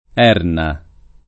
Erna [ $ rna ]